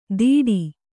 ♪ dīḍI